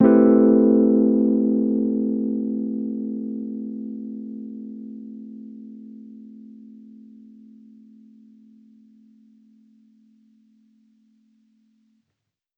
Index of /musicradar/jazz-keys-samples/Chord Hits/Electric Piano 1
JK_ElPiano1_Chord-Am9.wav